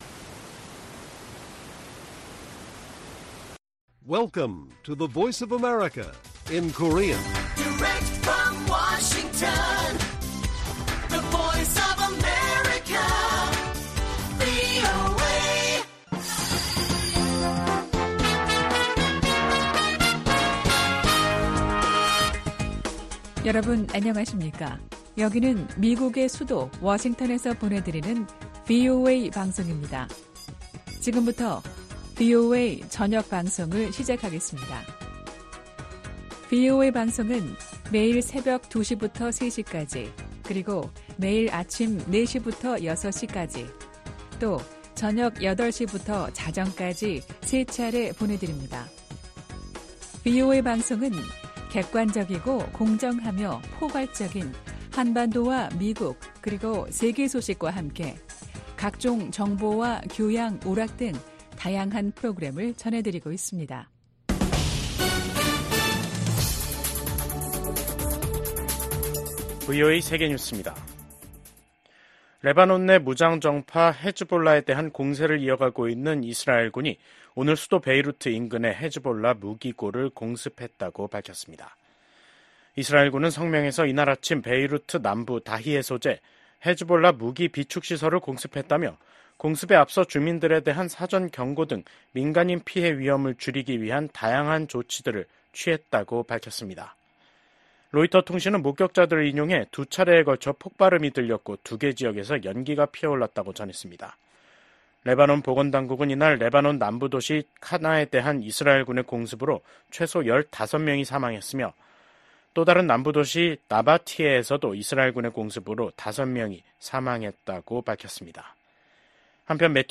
VOA 한국어 간판 뉴스 프로그램 '뉴스 투데이', 2024년 10월 16일 1부 방송입니다. 러시아의 방해로 해체된 유엔 대북 제재 감시의 공백을 메꾸기 위한 다국적 감시체제가 발족했습니다. 미국 정부는 북한이 한국과의 연결도로를 폭파하고 한국의 무인기 침투를 주장하며 군사적 대응 의사를 밝힌 데 대해 긴장 고조 행위를 멈출 것을 촉구했습니다.